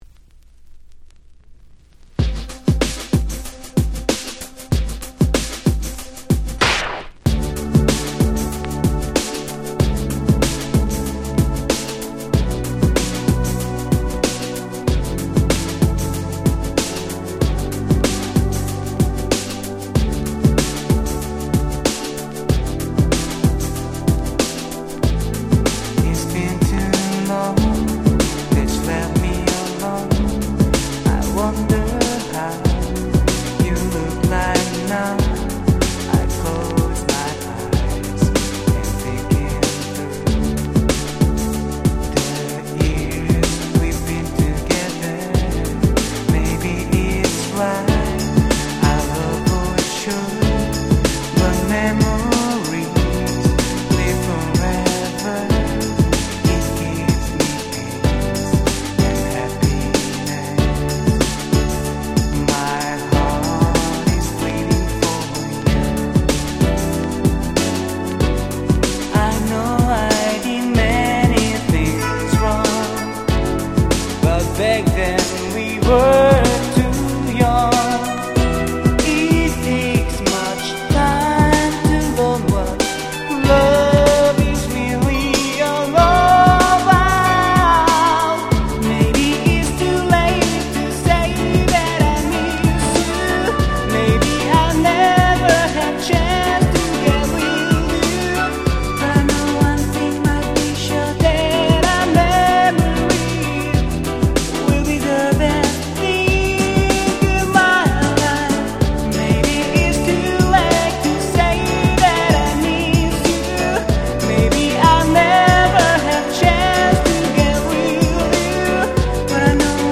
【Condition】C (全体的に薄いスリキズが多めですがDJ Play可。
※両面共に全トラック最初から最後まで試聴ファイルを録音してございます。
93' Very Nice Ground Beat !!